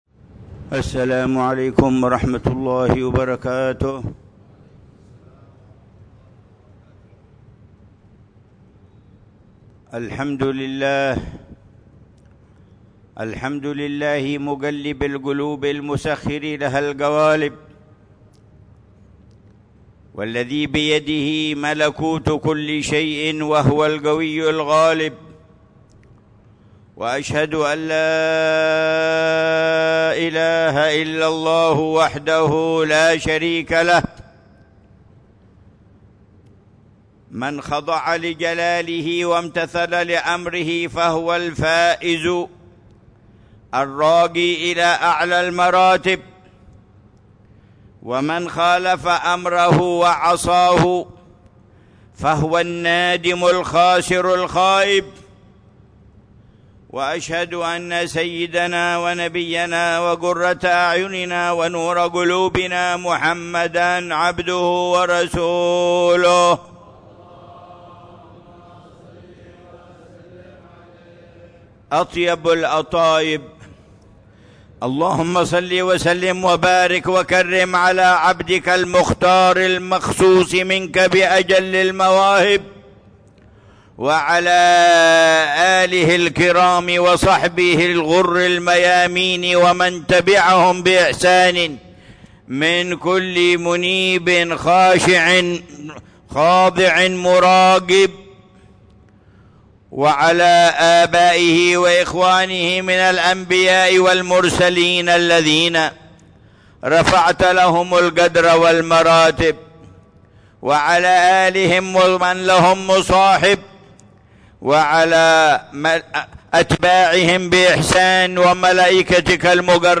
خطبة الجمعة
في جامع الروضة بعيديد، مدينة تريم